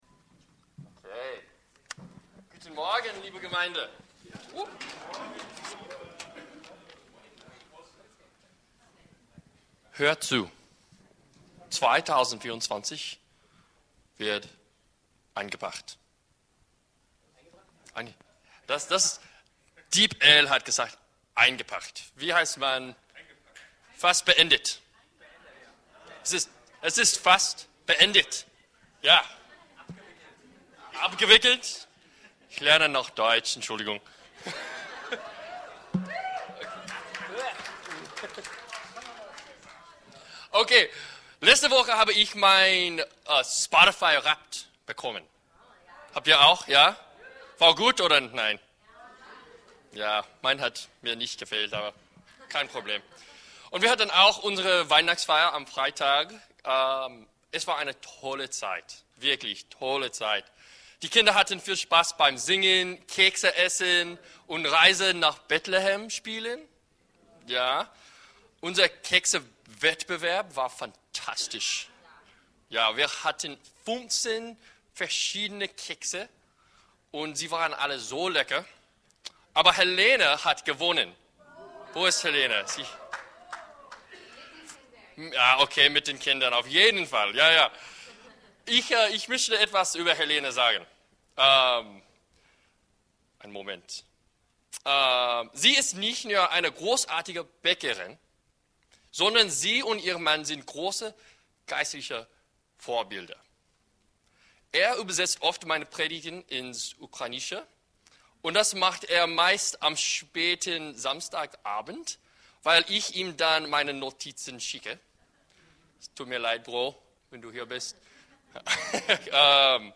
E-Mail Details Predigtserie: Advent Datum